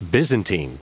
Prononciation audio / Fichier audio de BYZANTINE en anglais
Prononciation du mot : byzantine